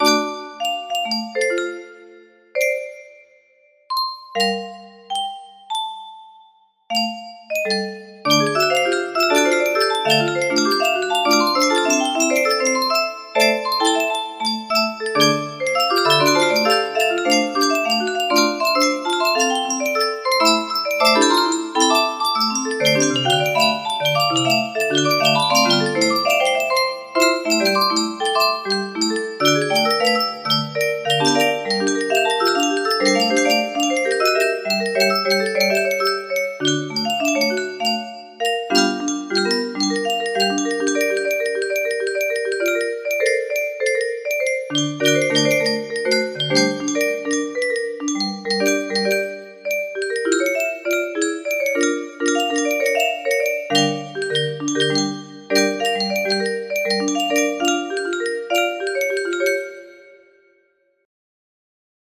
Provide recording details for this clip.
Imported from MIDI